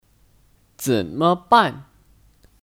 怎么办 Zěnme bàn (Kata tanya): Bagaimana (Untuk menanyakan cara)